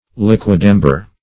Liquidamber \Liq"uid*am`ber\, n.